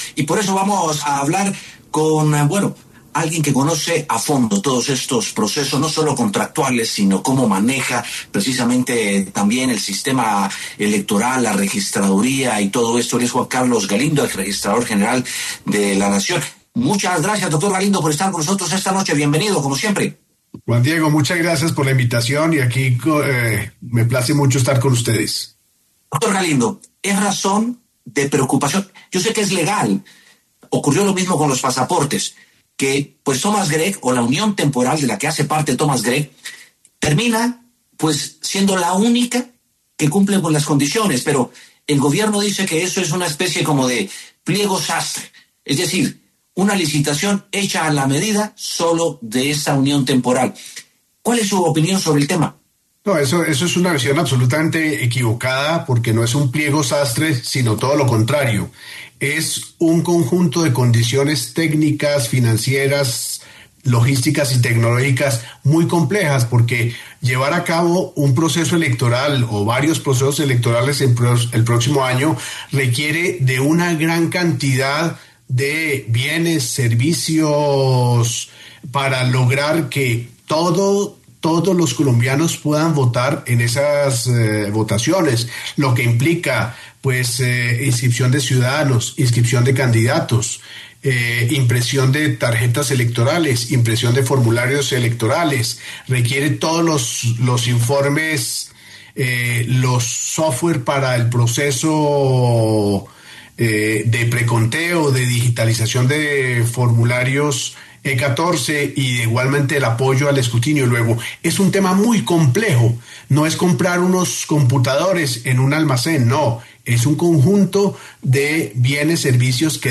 Juan Carlos Galindo, exregistrador de Colombia, pasó por los micrófonos de W Sin Carreta y habló sobre la posibilidad de que Thomas Greg & Sons sea quien produzca las elecciones del 2026.